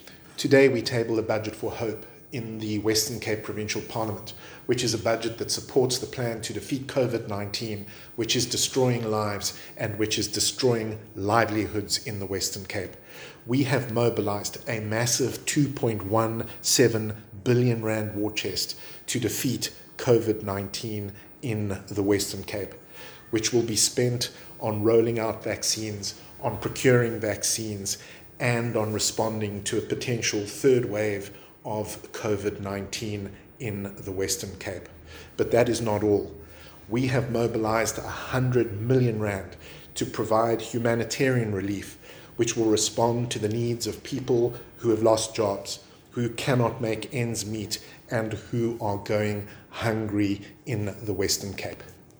Listen to Budget Statement by Minister David Maynier